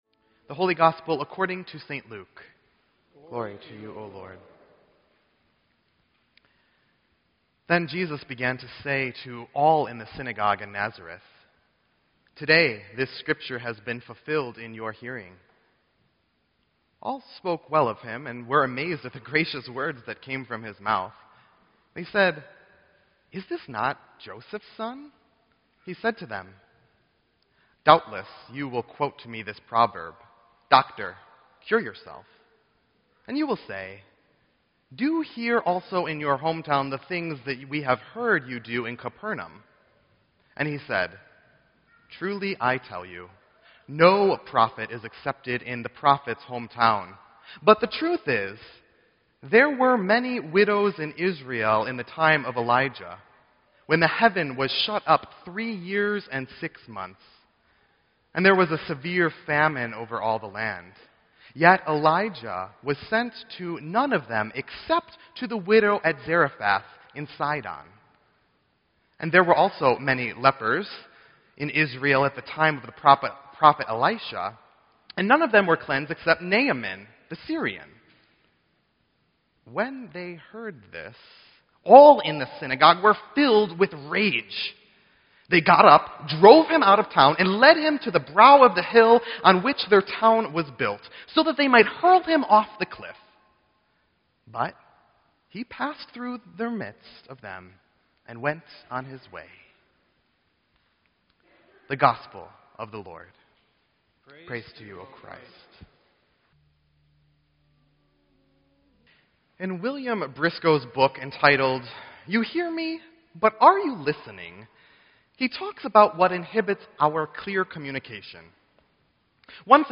Sermon_1_31_16.mp3